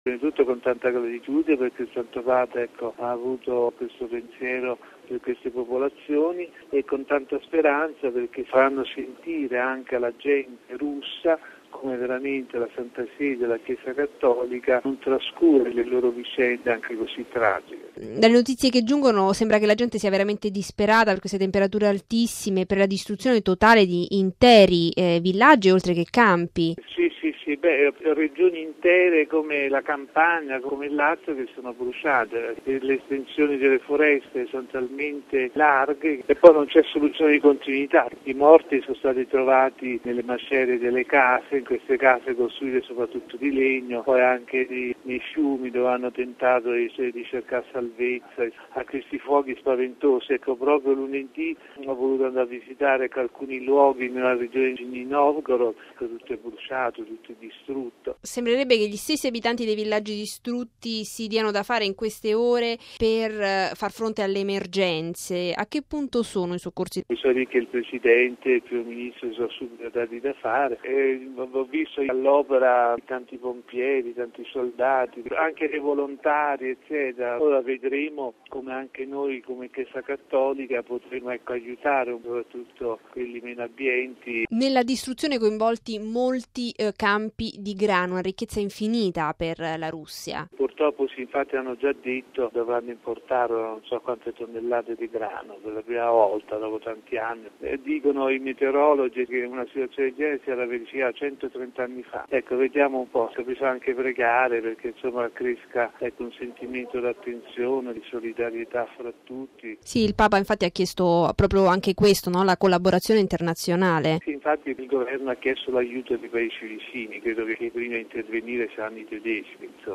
il nunzio apostolico nella Federazione Russa